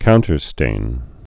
(kountər-stān)